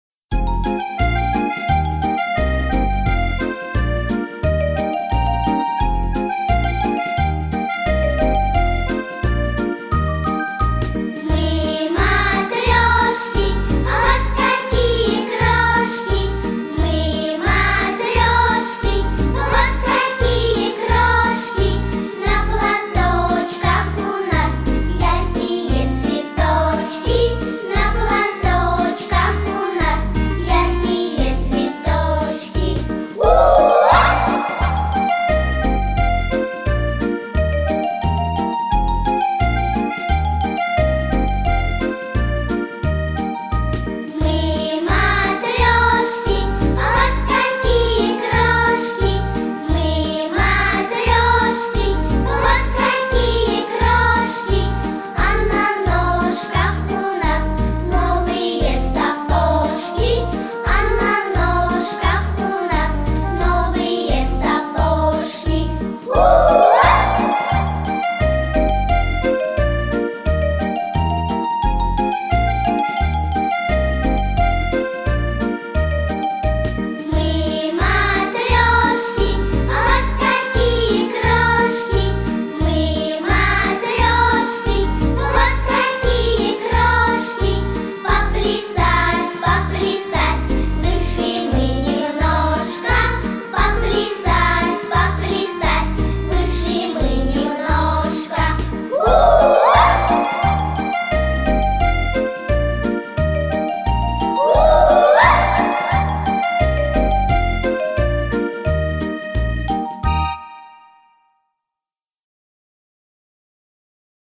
Главная / Песни для детей / Песни и музыка для танцев